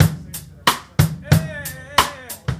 Rimhey 92bpm.wav